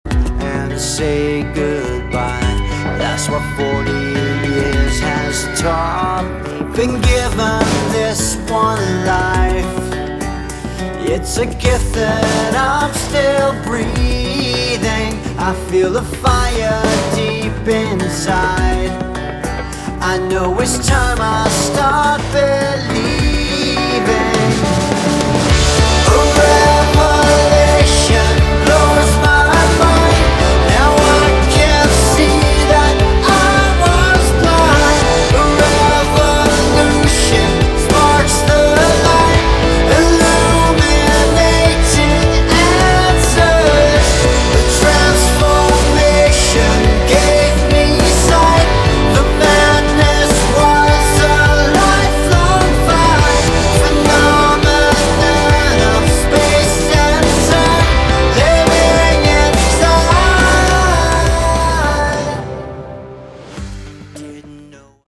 Category: Progressive Melodic Rock
vocals, drums, keys
keys, programming, sound design
bass guitar
electric and acoustic guitars